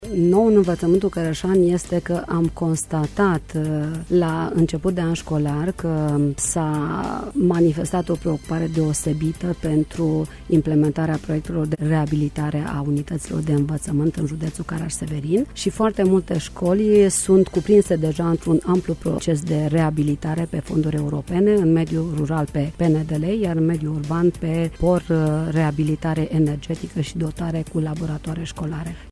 Ce este nou în învăţământul cărăşean? În studioul Radio Reşiţa s-a aflat inspectorul şcolar general, Lenuţa Ciurel